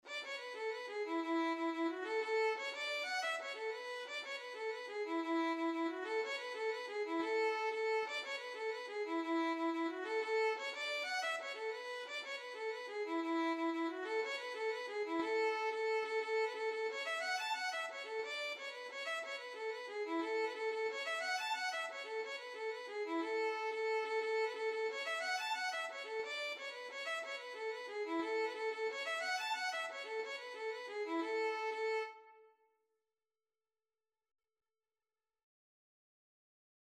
A major (Sounding Pitch) (View more A major Music for Violin )
6/8 (View more 6/8 Music)
Violin  (View more Intermediate Violin Music)
Traditional (View more Traditional Violin Music)
Irish